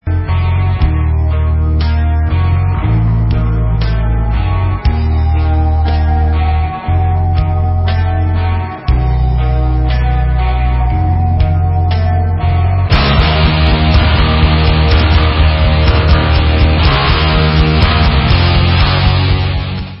britská heavymetalová kapela
sledovat novinky v kategorii Rock